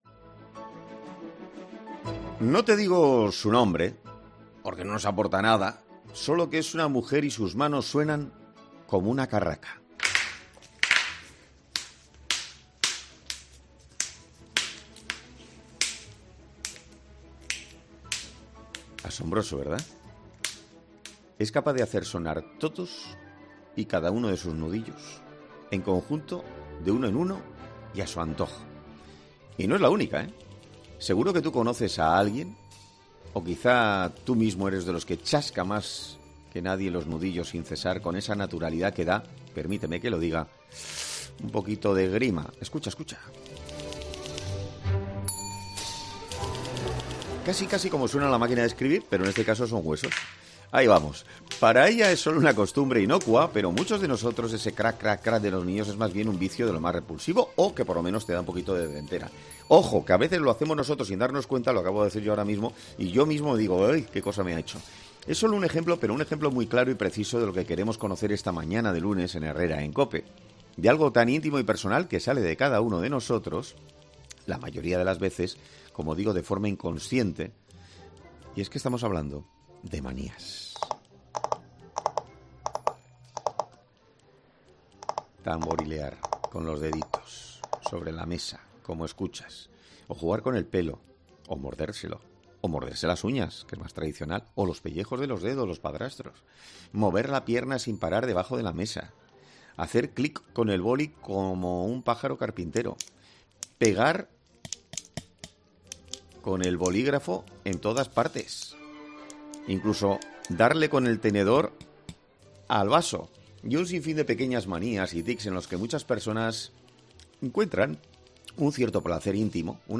En 'Herrera en COPE' hablamos con el doctor